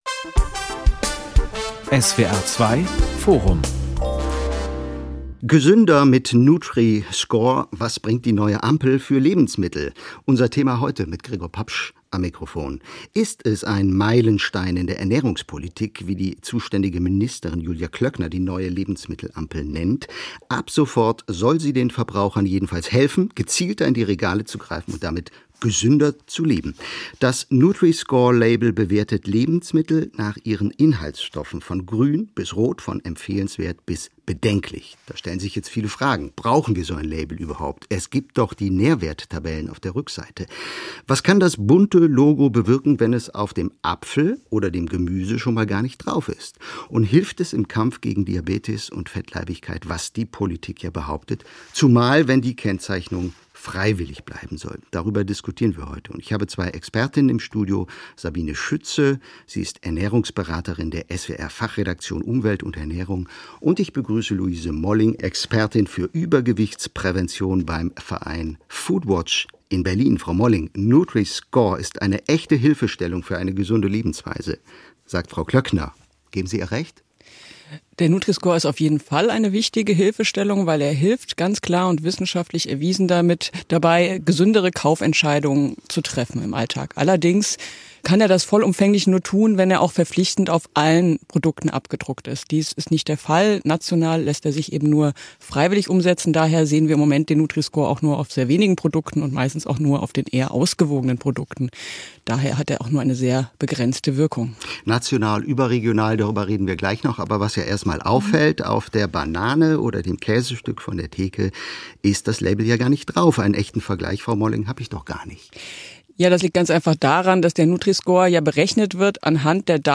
Nutri-Score bewertet Lebensmittel nach ihren Inhaltsstoffen – von grün bis rot, empfehlenswert bis bedenklich. Was kann das neue Label bewirken? Sendung vom Di, 27.10.2020 17:05 Uhr, SWR2 Forum, SWR2